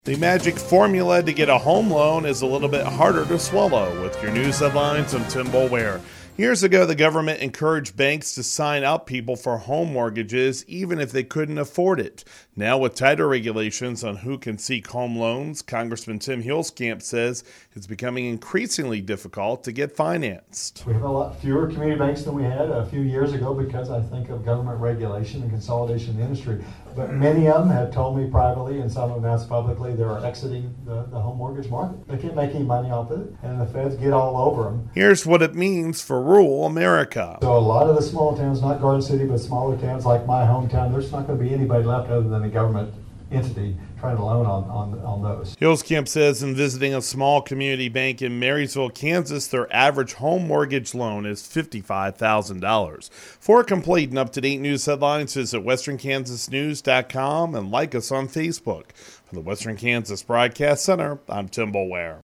Congressman Tim Huelskamp noted the discovery during Tuesday’s townhall meeting in Garden City at Ward’s Cafe.
*On-air story*